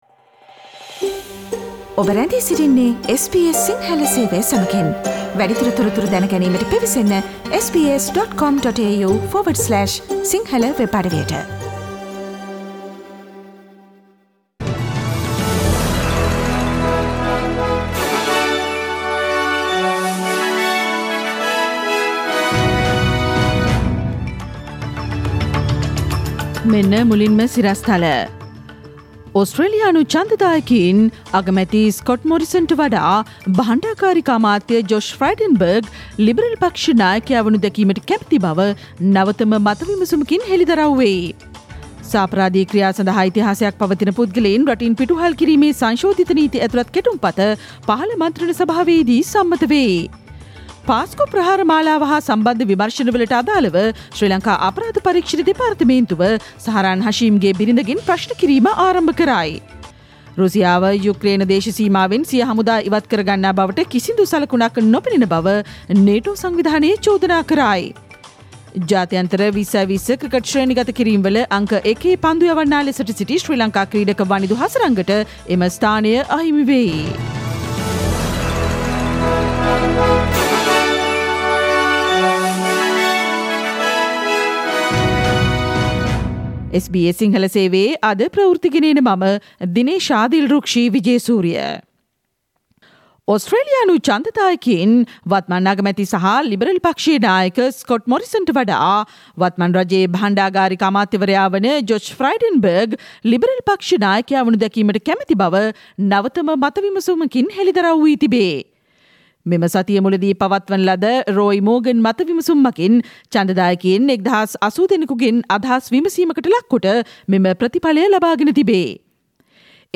පෙබරවාරි 17 වන බ්‍රහස්පතින්දා SBS සිංහල ගුවන්විදුලි වැඩසටහනේ ප්‍රවෘත්ති ප්‍රකාශයට සවන්දෙන්න ඉහත චායාරූපය මත ඇති speaker සලකුණ මත click කරන්න